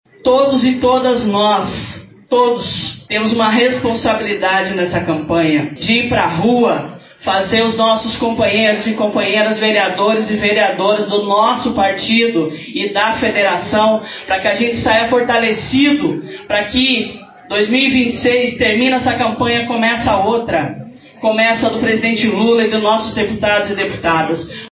A convenção aconteceu na sede da Federação dos Trabalhadores nas Indústrias da Construção e do Mobiliário do Estado do Paraná (Fetraconspar), onde os filiados do PT votaram a favor do apoio ao pré-candidato do PSB para o Executivo.